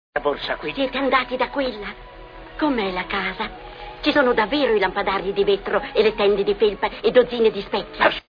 film "Via col vento", in cui doppia Leona Roberts.